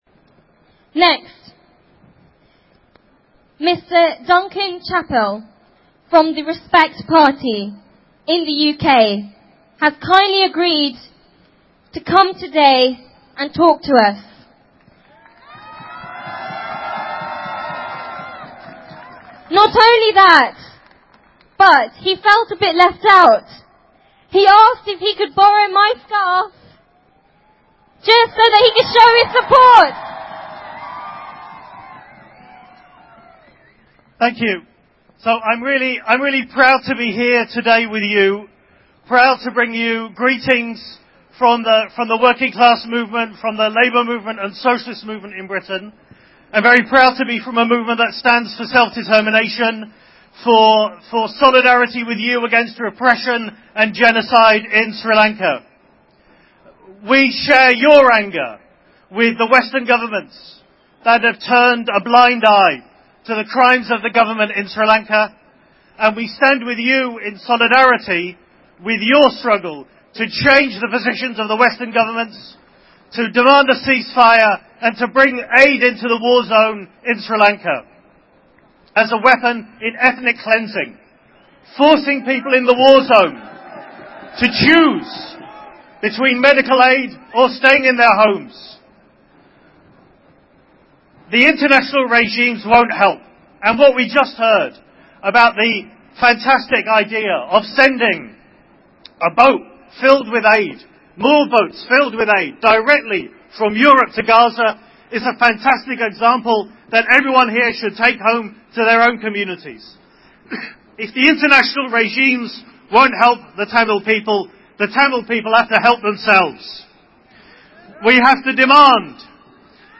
Voice: Address